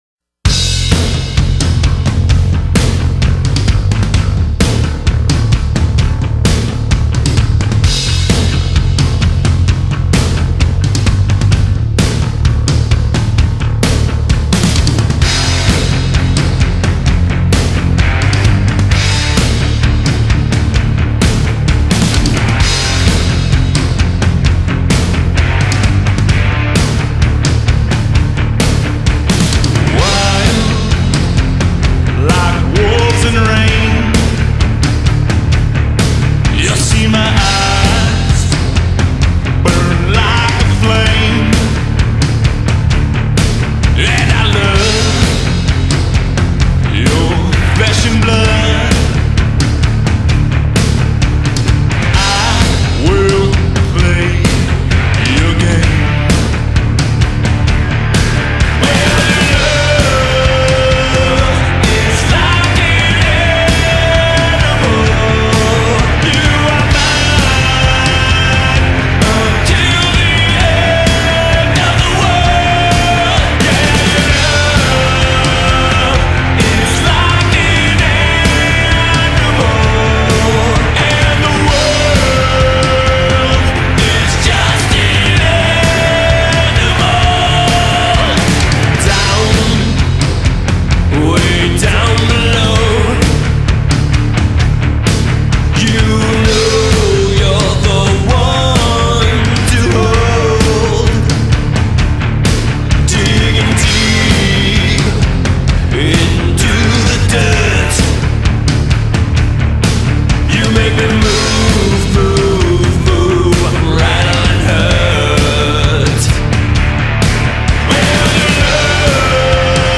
darker, grittier, and heavier than ever before!